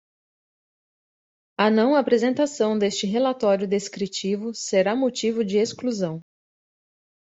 /a.pɾe.zẽ.taˈsɐ̃w̃/